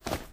High Quality Footsteps / Dirt
STEPS Dirt, Run 18.wav